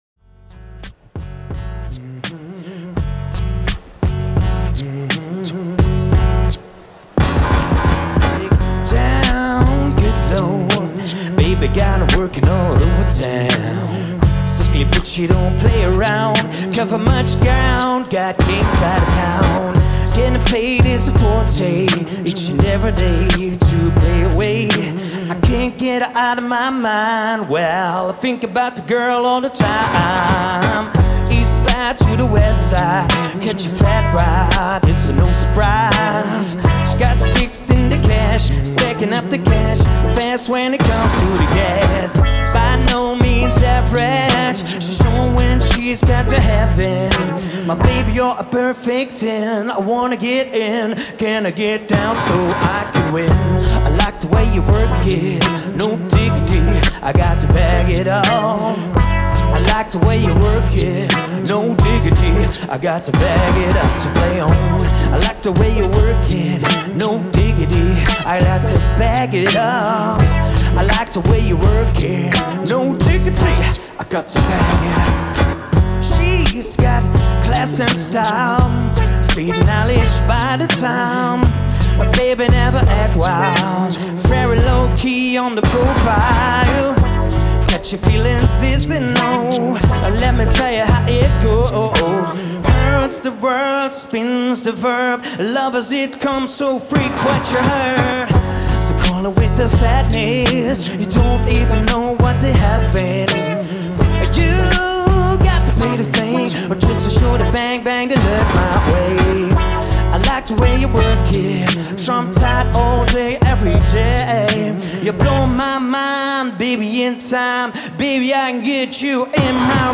one man band